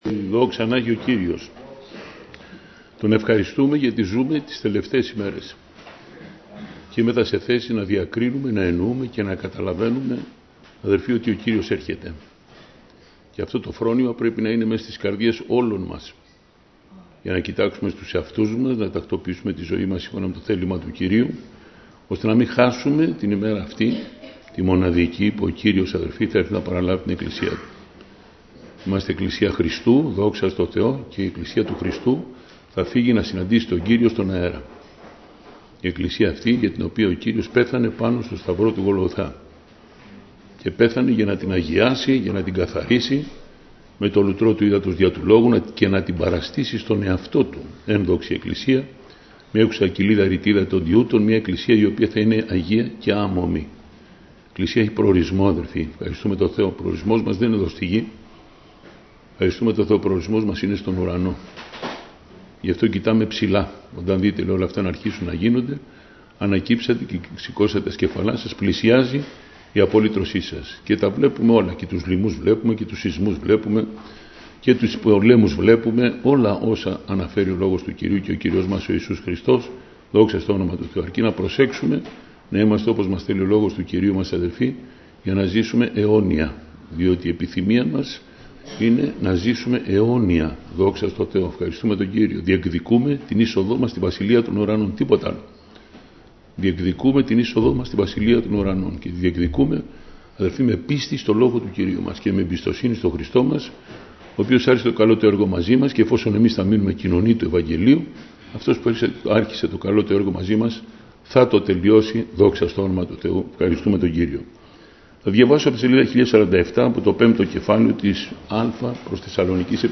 Σειρά: Κηρύγματα